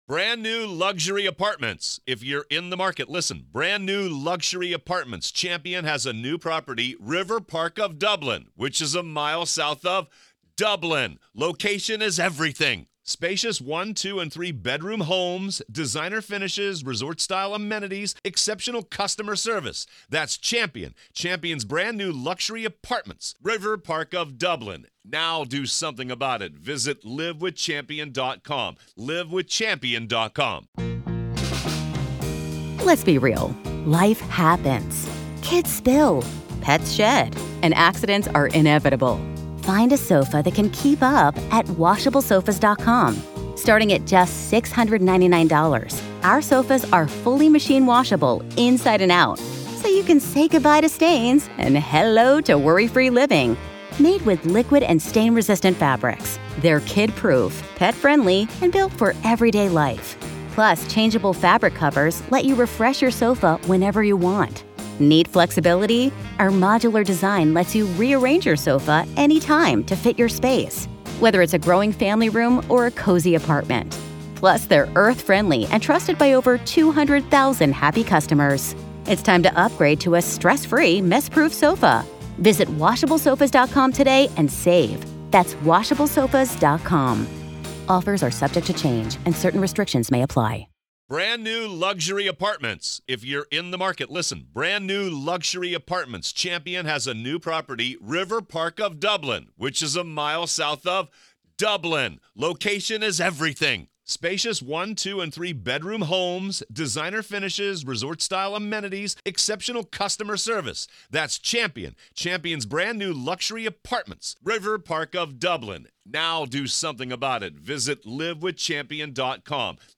Listen to the full courtroom trial coverage of Alex Murdaugh. This is our continuing coverage of the Alex Murdaugh murder trial.